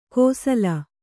♪ kōsala